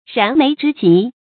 注音：ㄖㄢˊ ㄇㄟˊ ㄓㄧ ㄐㄧˊ
燃眉之急的讀法